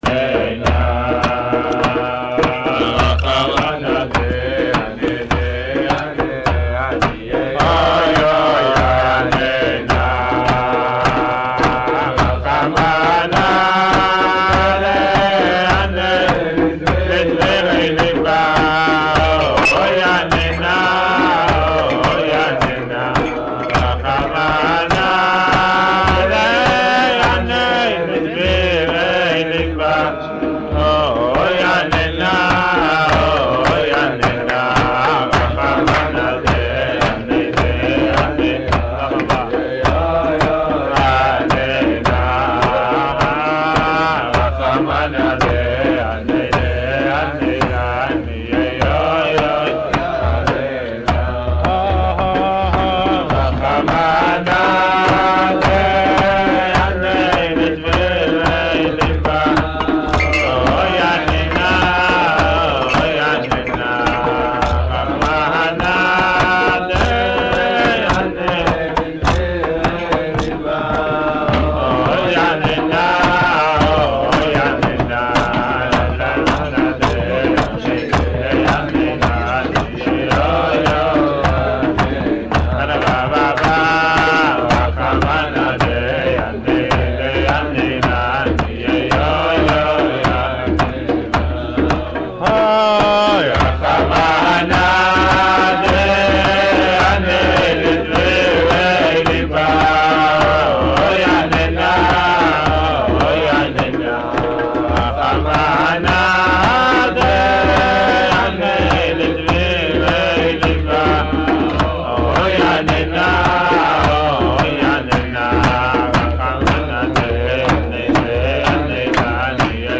שיעור לקראת יום כיפור